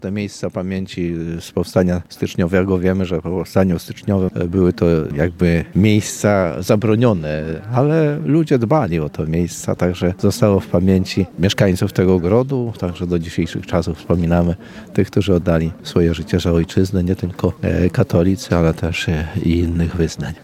Głównym punktem uroczystości było podniesienie i poświęcenie krzyża na „Mokrej Łączce” – miejscu kaźni bohaterów Powstania.
Poświęcenia nowego krzyża dokonał biskup łomżyński Janusz Stepnowski.